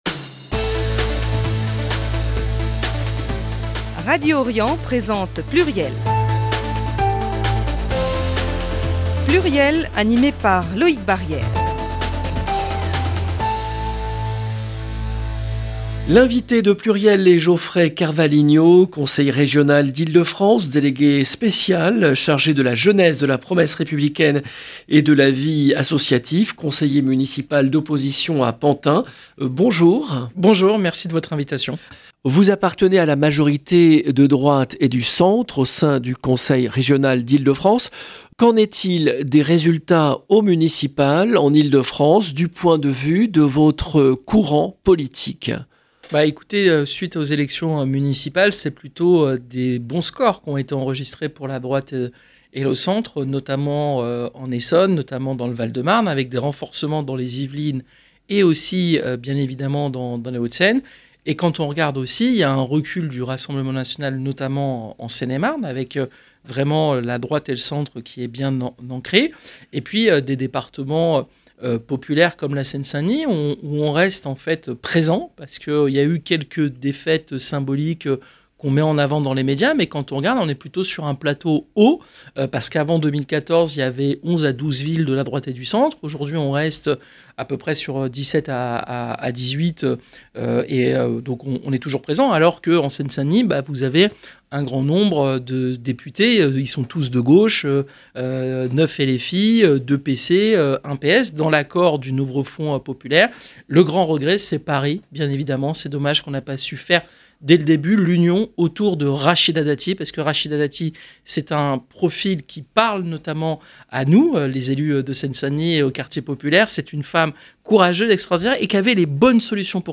Lors de cette interview, il apporte également son soutien à Bally Bayoko, le maire LFI de Saint-Denis, victime de propos racistes, et aux maires sortants battus aux élections qui ont été chahutés et insultés après leur défaite électorale. 0:00 15 min 34 sec